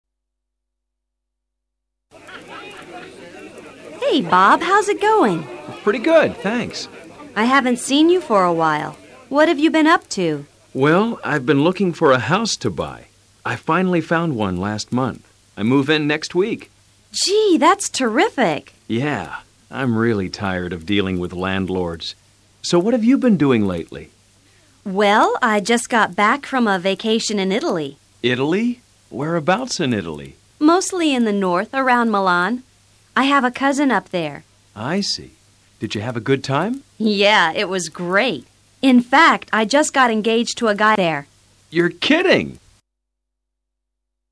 En la misma fiesta se encuentran también Jennifer y Bob. Escucha con atención y repite luego el diálogo.